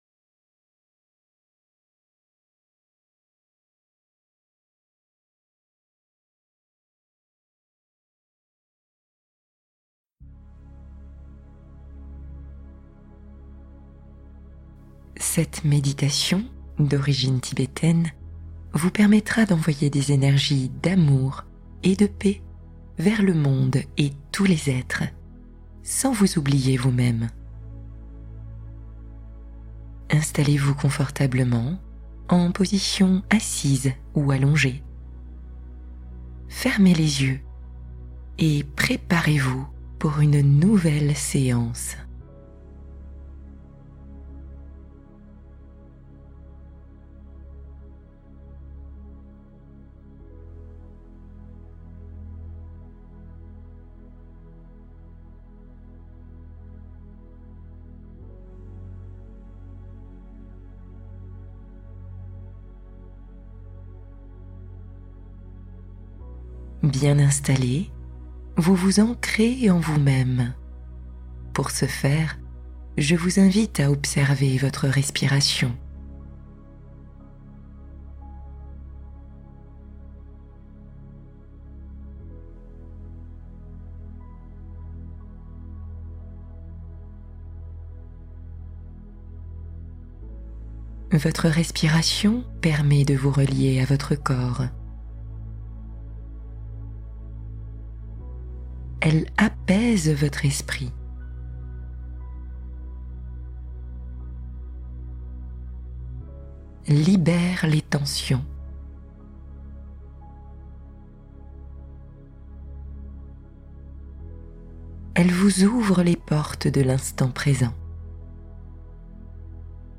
Ouvrez votre cœur en 20 minutes | Méditation d'amour bienveillant pour guérir vos blessures